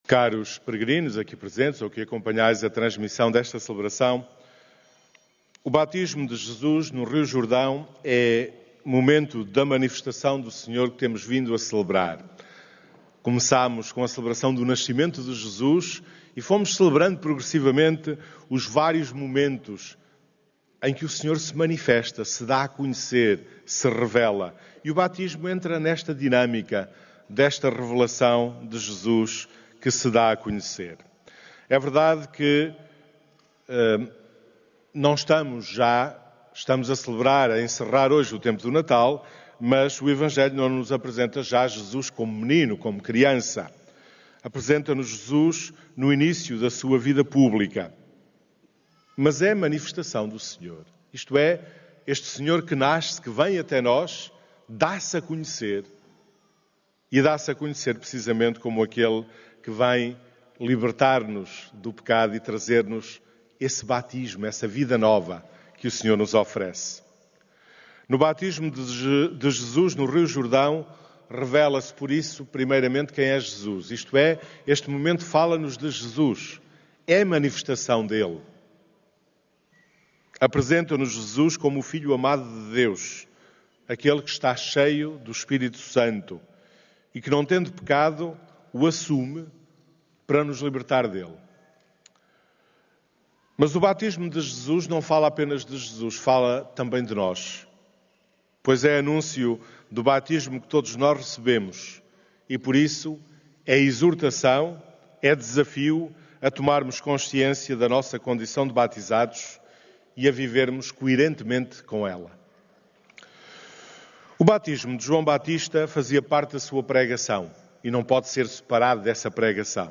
Áudio da homilia